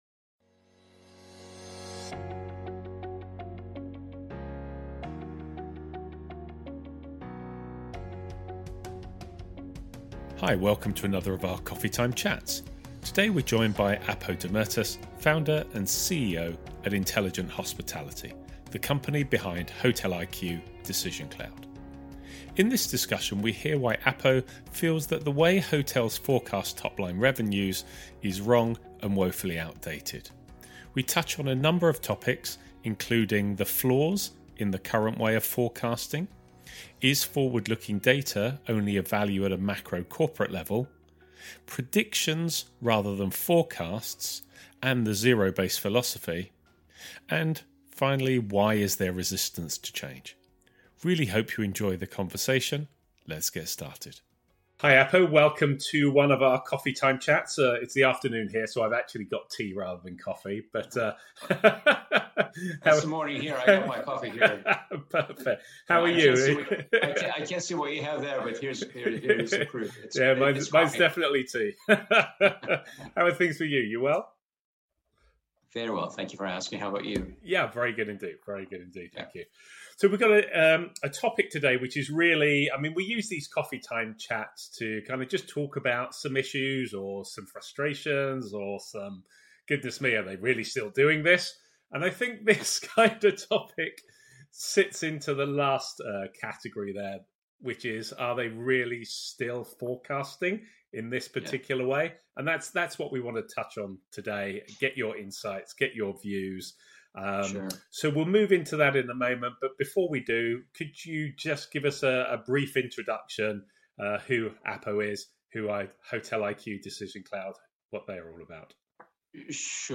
Welcome to another of our Coffee Time chats.